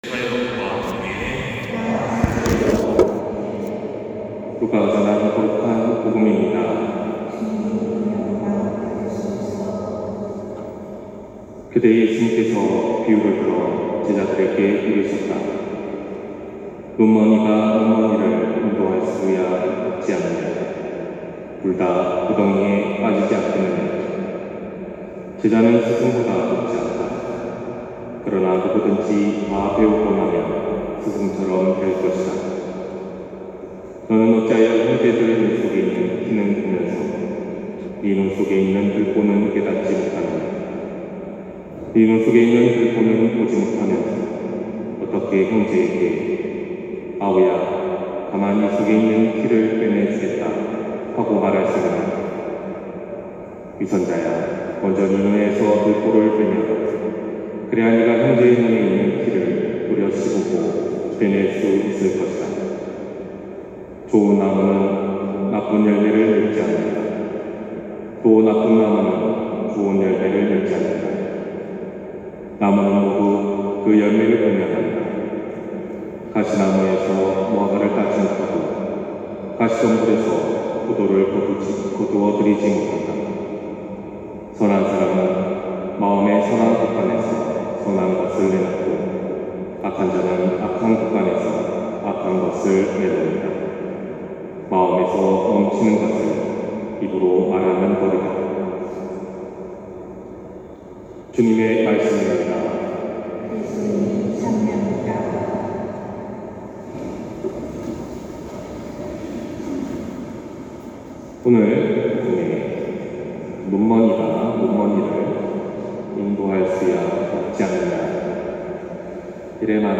250301신부님 강론말씀